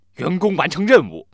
angry